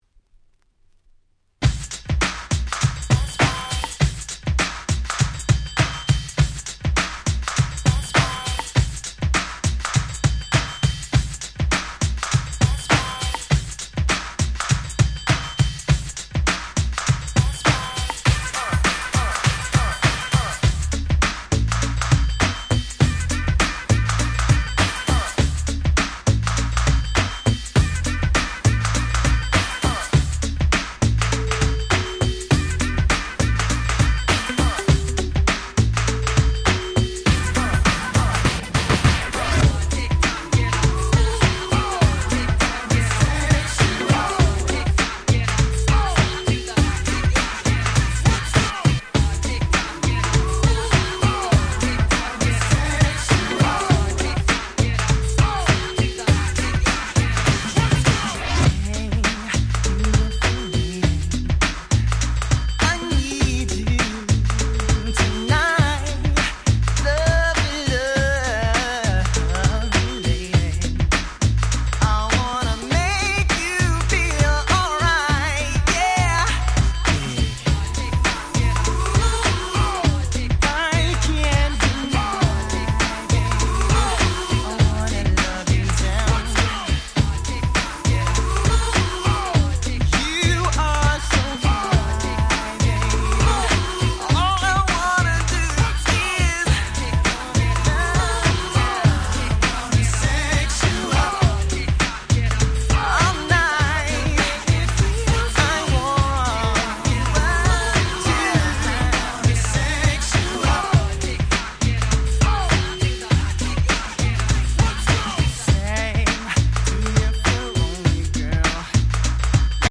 White Press Only Remix !!